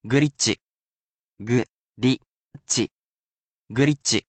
I will be there to repeat the word and its pronunciation as many times as you wish.